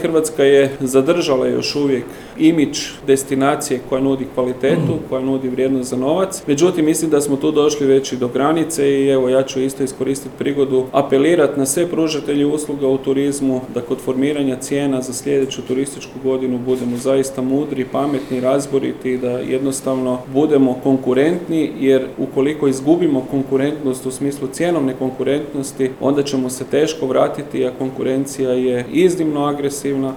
Da će cijena biti dominantan faktor slaže se i direktor HTZ-a Kristjan Staničić.